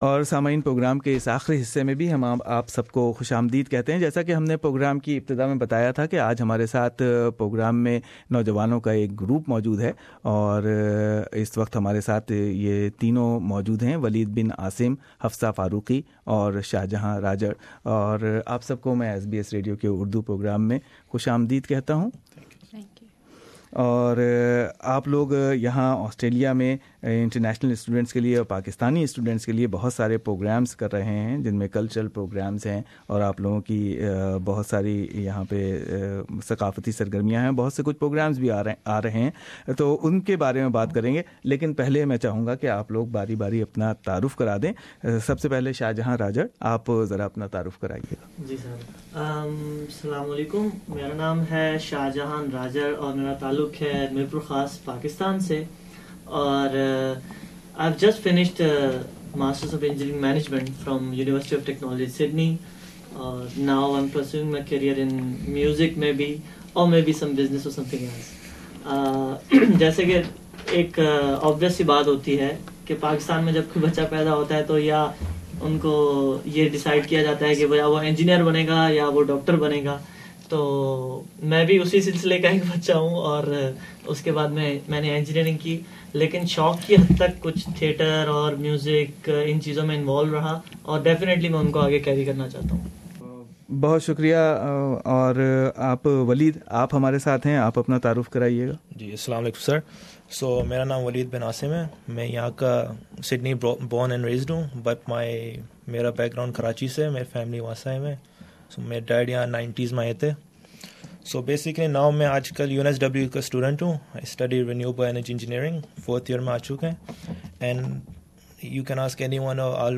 Student group sharing their joint ventures and upcoming programs.